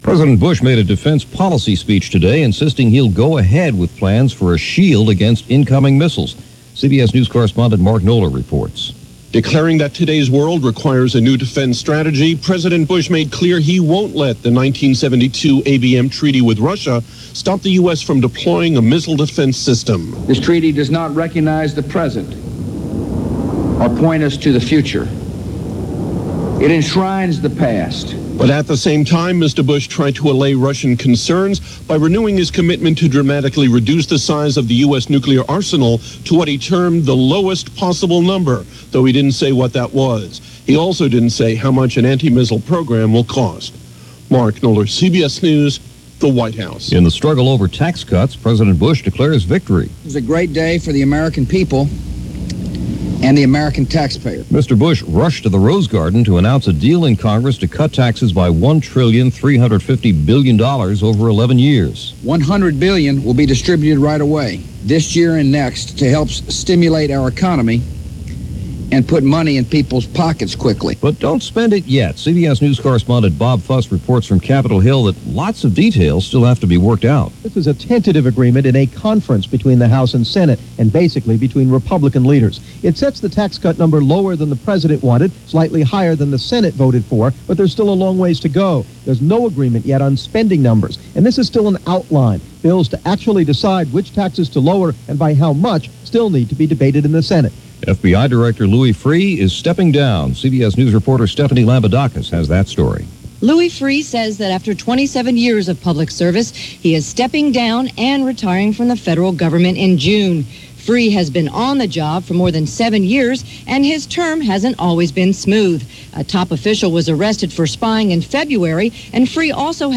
And that’s a small sample of what went on, this May 1st in 2001 as reported by The CBS World News Roundup; Late Editon